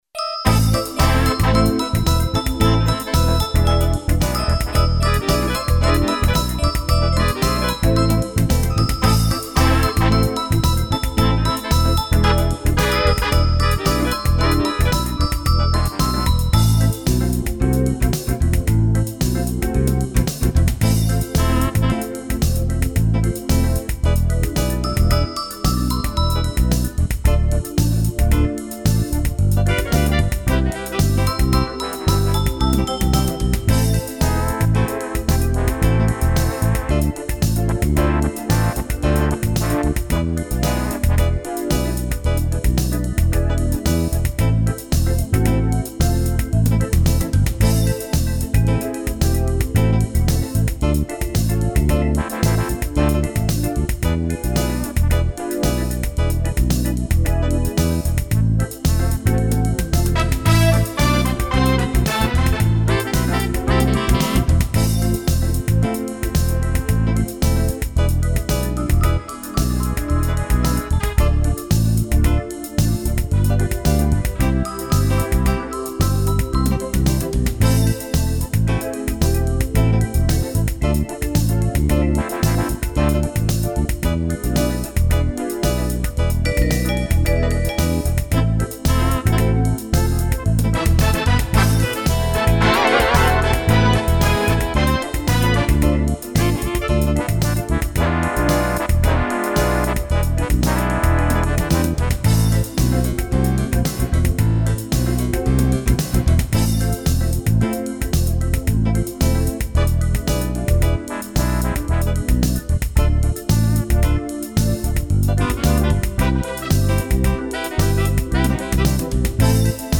(ремикс) (минус)